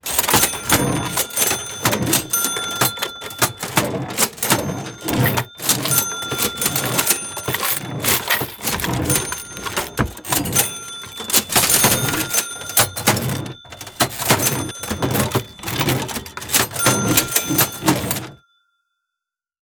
Classic Cash Registers SFX
Detailed high quality recordings of four vintage cash registers.
Opening and closing sounds, with and without coins in the drawers.
Recorded at a variety of different speeds and distances.
cash-registers-demo.wav